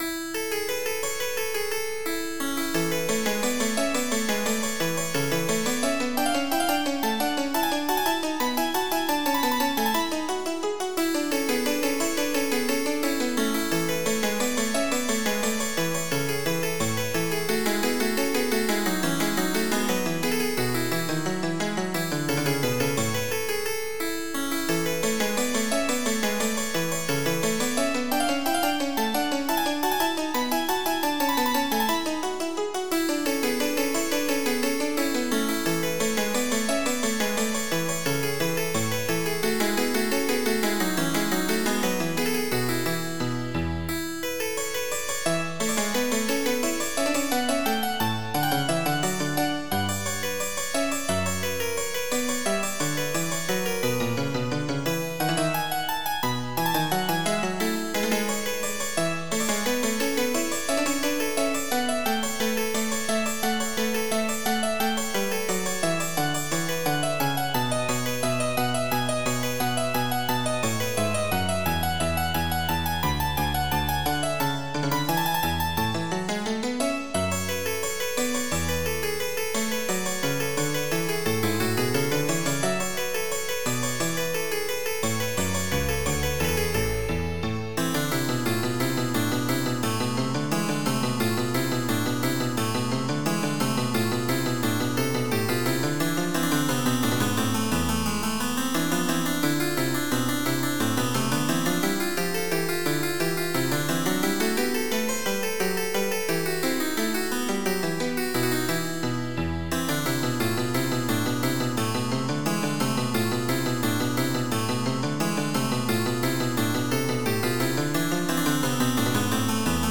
MIDI Music File
Type General MIDI
bourree1.mp3